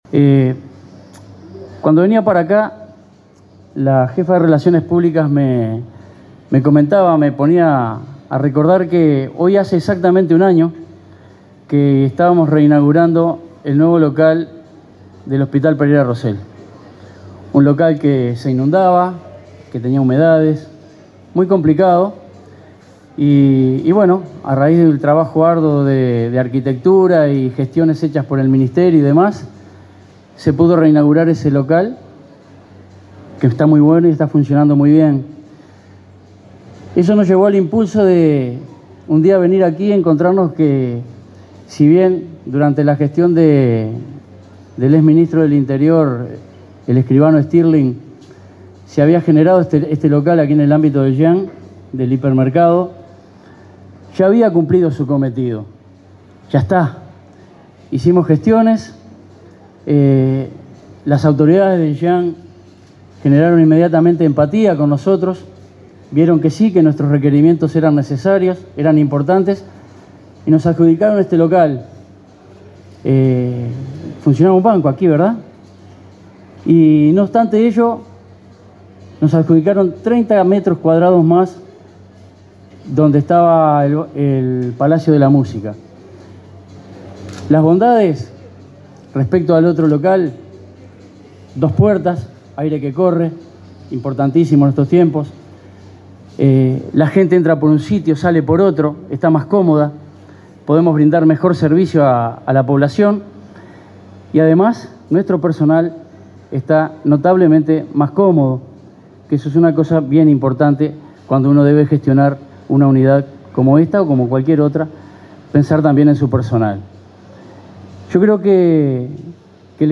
Palabras de autoridades en reinauguración de oficina de Identificación Civil
Palabras de autoridades en reinauguración de oficina de Identificación Civil 14/07/2022 Compartir Facebook X Copiar enlace WhatsApp LinkedIn El director nacional de Identificación Civil, José Luis Rondán, y el ministro del Interior, Luis Alberto Heber, participaron este jueves 14 en la reinauguración de la oficina de esa repartición en el centro comercial Parque Roosevelt, en Canelones.